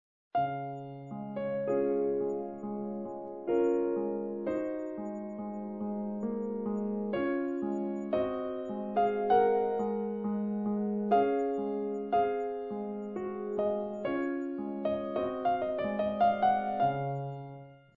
Vier Pianisten - 4 mal Tempo rubato
› Interpretation des Spielers 3 [MP3 | 67 KB] (Aufnahme am MIDI-Flügel, wiedergegeben mit dem Soundmodul „VST Grand Piano” der Fa. Steinberg)